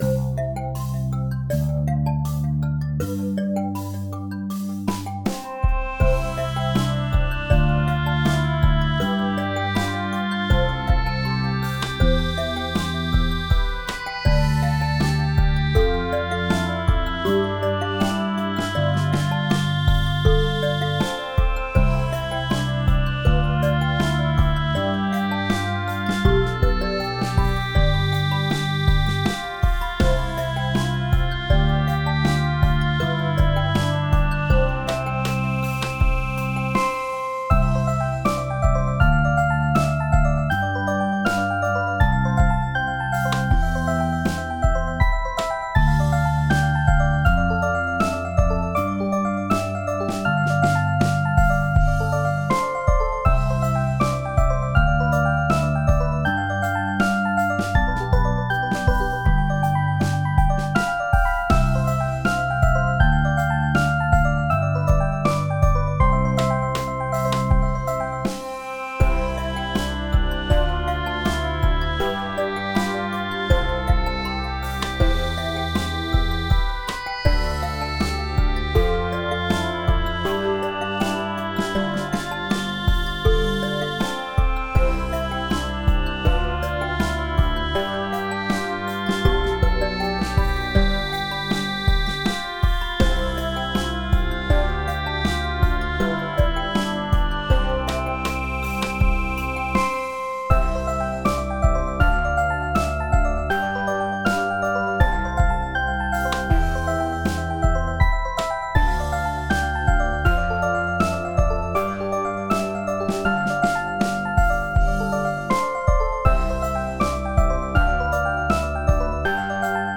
Bestevenen: Ei vise til songdame